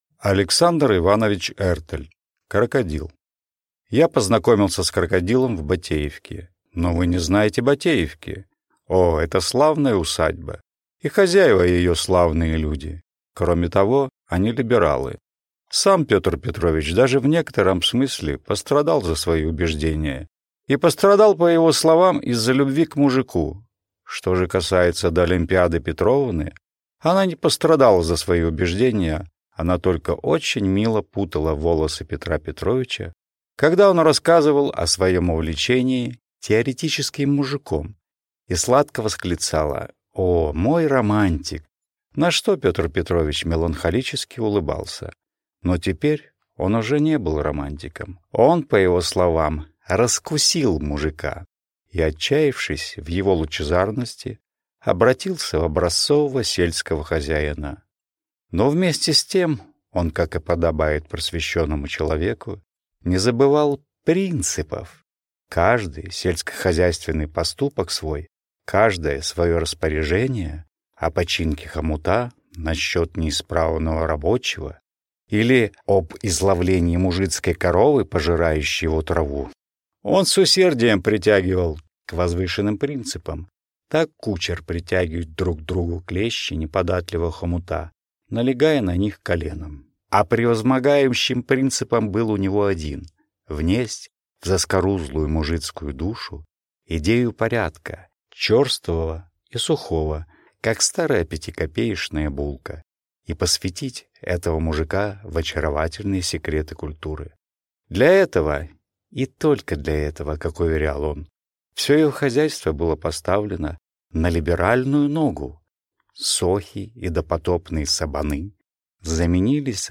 Аудиокнига Крокодил | Библиотека аудиокниг